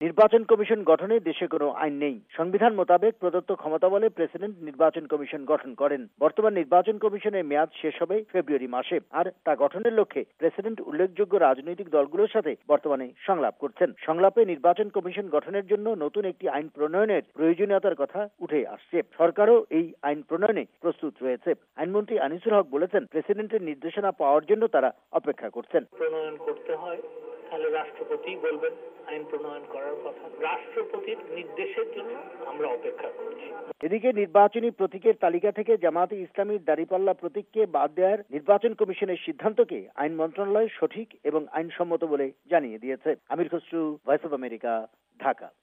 ঢাকা থেকে
প্রতিবেদন